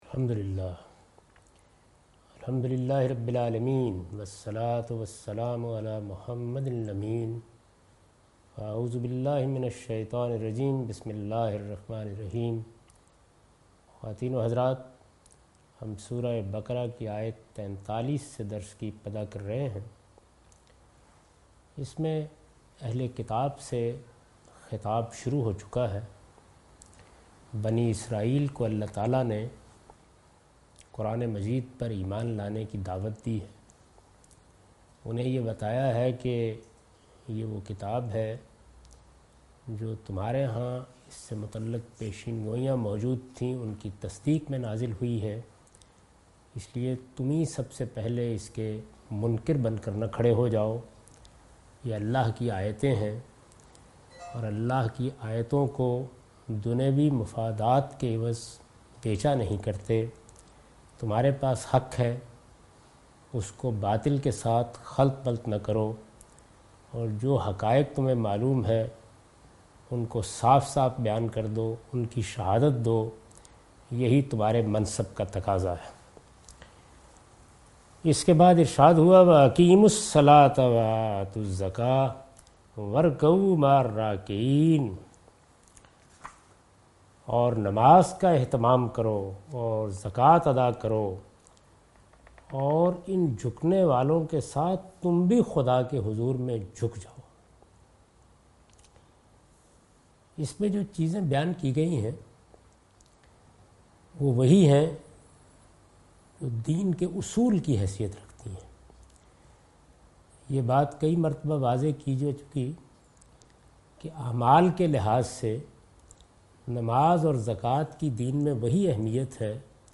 Surah Al-Baqarah - A lecture of Tafseer-ul-Quran – Al-Bayan by Javed Ahmad Ghamidi. Commentary and explanation of verse 43,44,45 and 46 (Lecture recorded on 23rd May 2013).